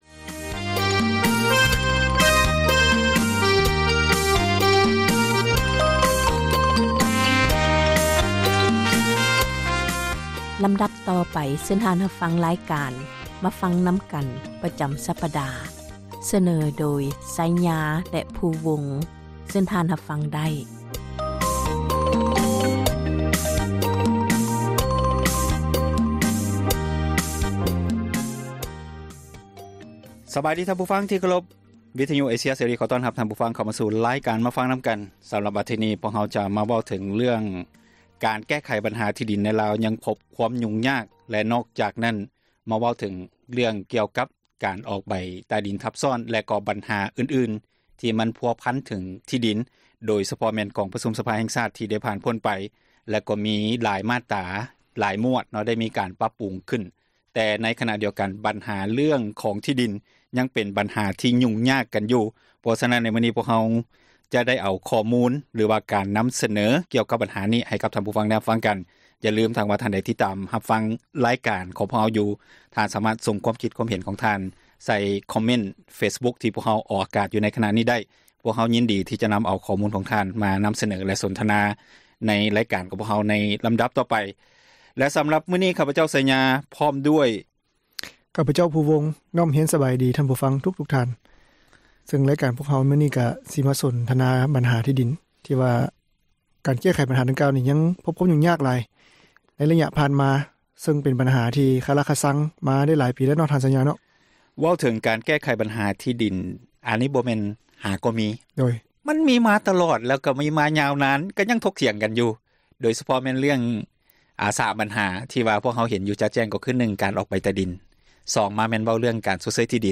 "ມາຟັງນຳກັນ" ແມ່ນຣາຍການສົນທະນາ ບັນຫາສັງຄົມ ທີ່ຕ້ອງການ ພາກສ່ວນກ່ຽວຂ້ອງ ເອົາໃຈໃສ່ແກ້ໄຂ,